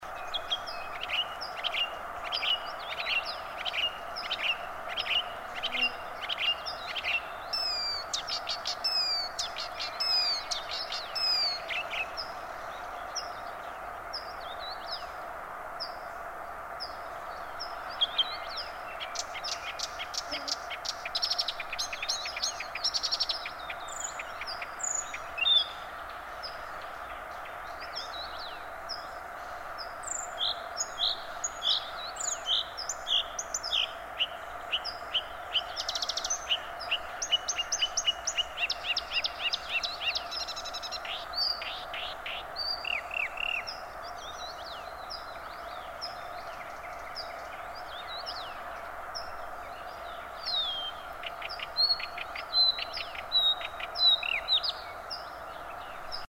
Imitations in recorded song of hybrid Marsh x Blyth's Reed Warbler Acrocephalus palustris x dumetorum, Espoo, Finland, 19 June 2003
0:00 loud calls of Common Bulbul Pycnonotus barbatus
0:35 Common Bulbul, then disyllabic unidentified sound, then high laughter, which is a frequent motif in palustris, an unidentified imitation
0:41 unidentified, then 'rue-rue-rue' of Blue-cheeked Bee-eater, very clear.